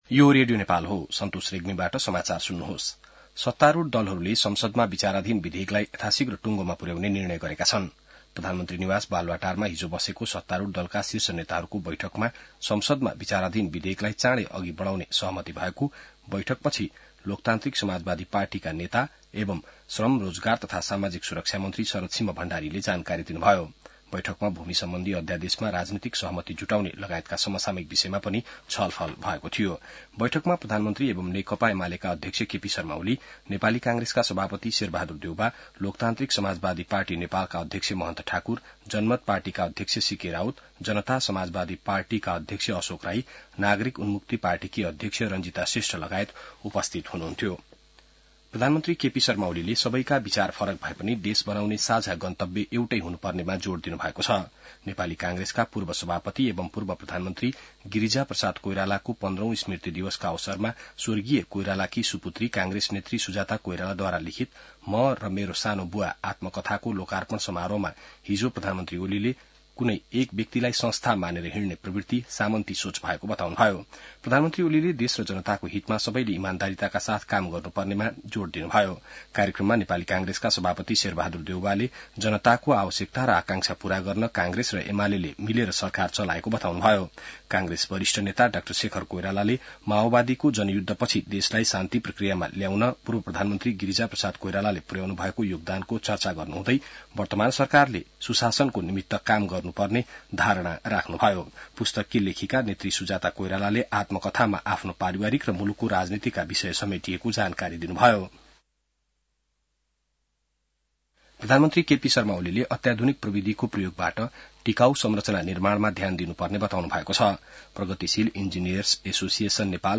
बिहान ६ बजेको नेपाली समाचार : १३ चैत , २०८१